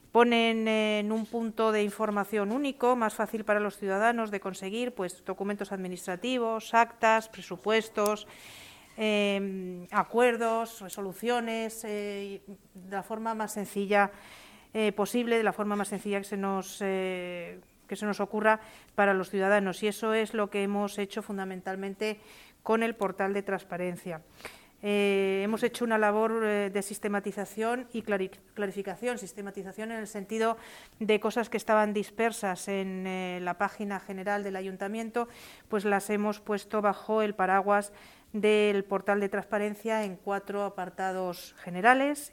AUDIOS. Mar Álvarez, concejala de Hacienda